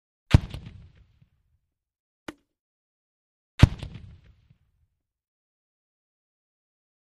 Grenade Launcher; One Medium Distant Grenade Explosion With Echo Followed By A Distant Launch And Another Explosion.